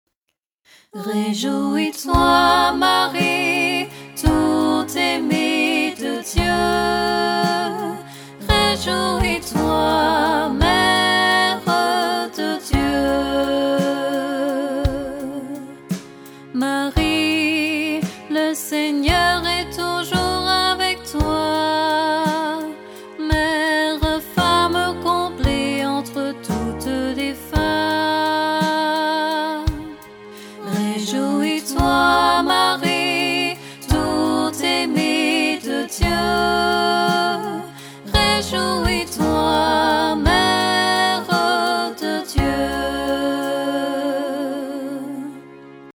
Chant & guitare
Extraits cantiques religieux